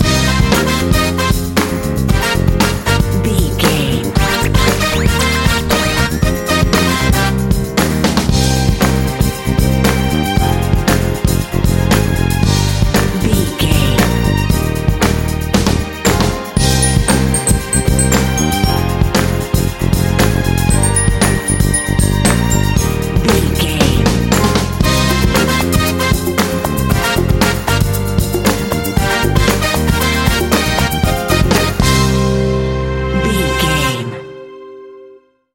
Aeolian/Minor
funky
happy
bouncy
groovy
piano
bass guitar
percussion
drums
brass
strings
synthesiser
Funk